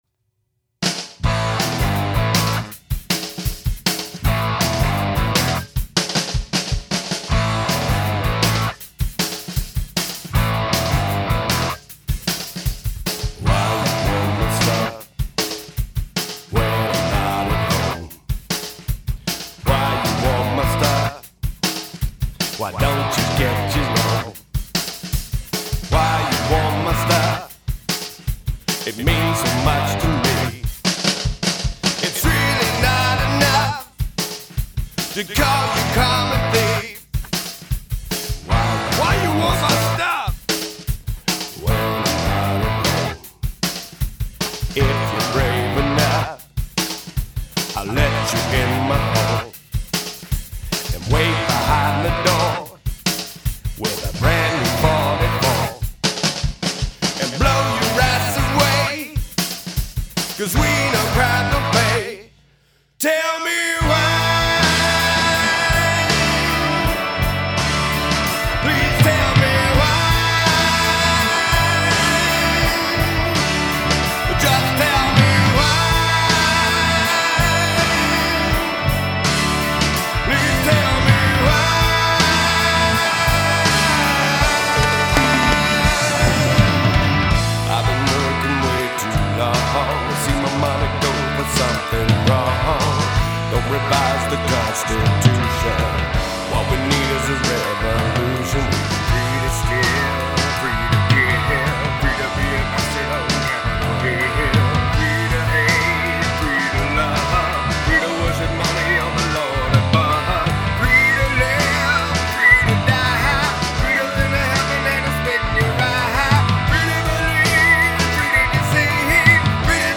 Alternative/Country/Soul